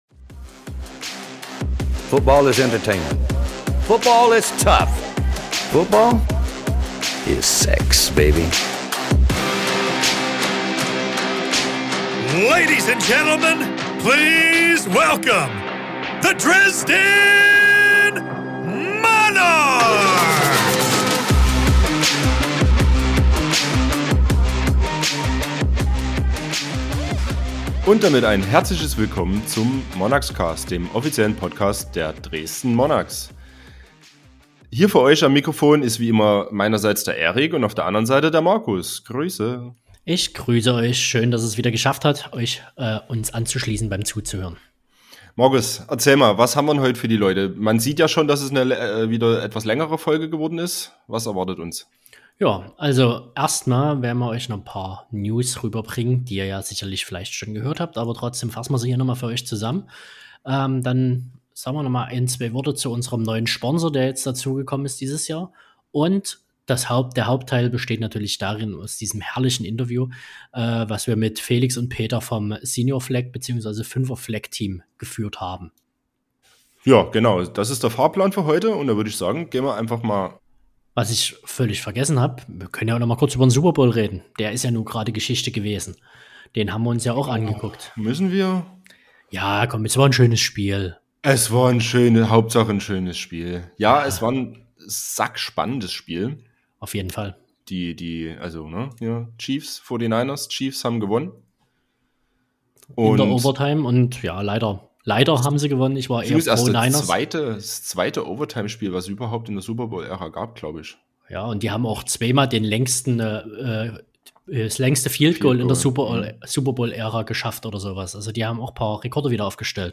Offseason Talk